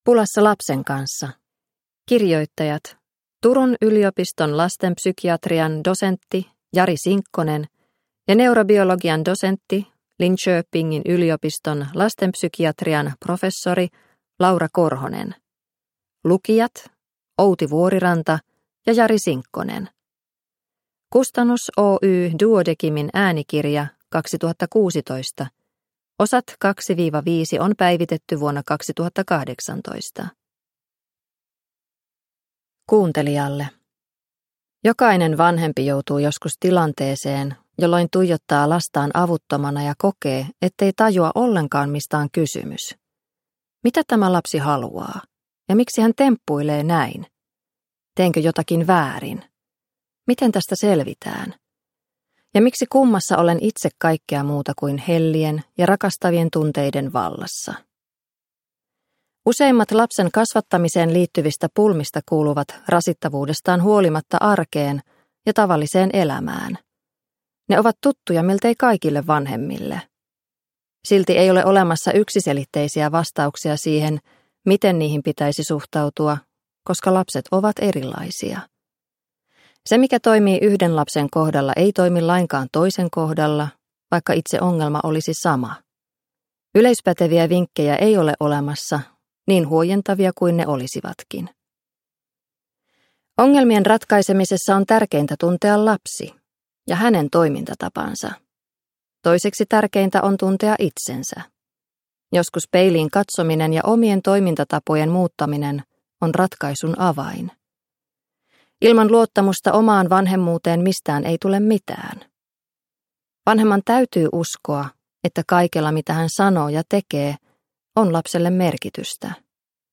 Pulassa lapsen kanssa – Ljudbok – Laddas ner